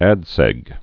(ăd sĕg)